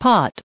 1290_pot.ogg